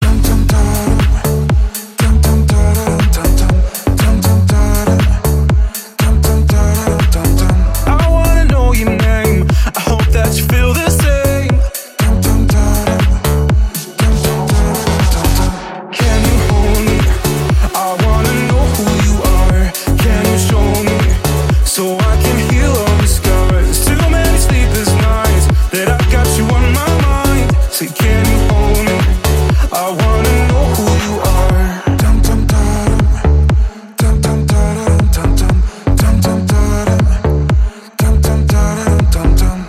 • Качество: 128, Stereo
deep house